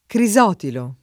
crisotilo [ kri @0 tilo ]